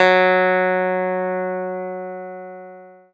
CLAVI1.05.wav